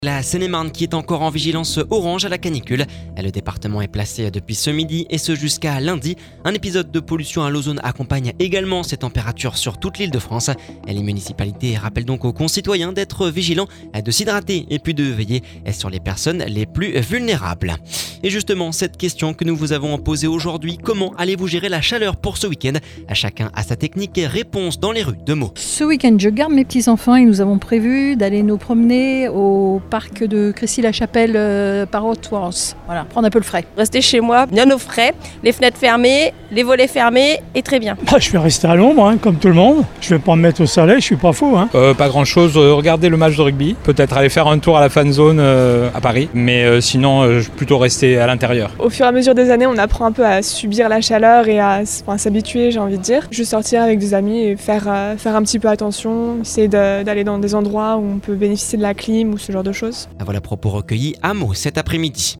Tout le monde a sa technique, réponses dans les rues de Meaux…